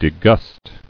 [de·gust]